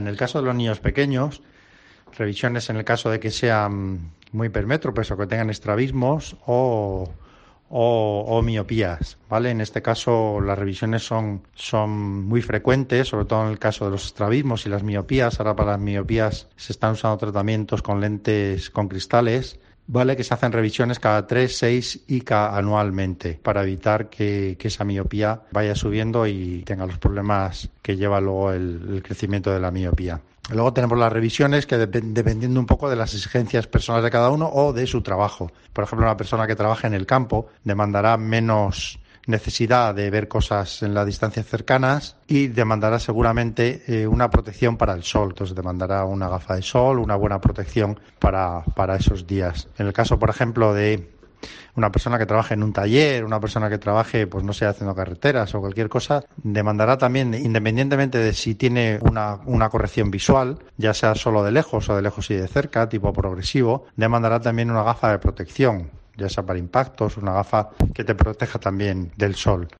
óptico